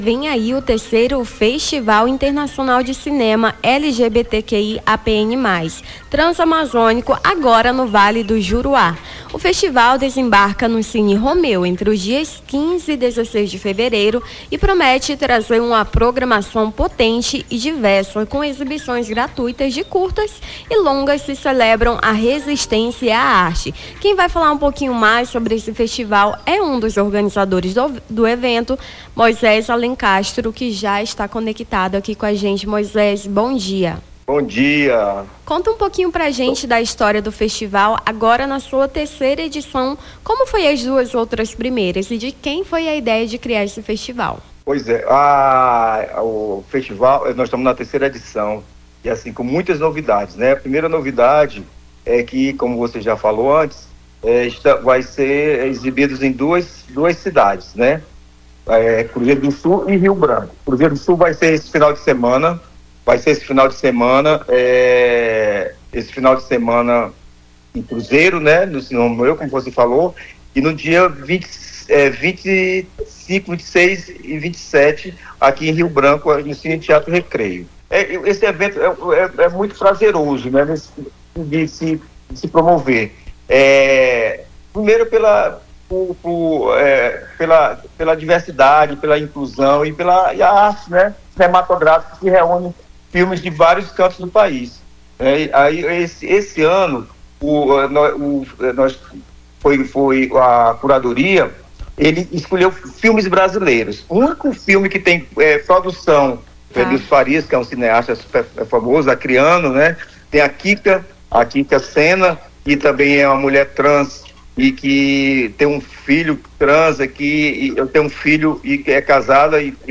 Nome do Artista - CENSURA - ENTREVISTA FESTIVAL TRANSAMAZÔNICO (11-02-25).mp3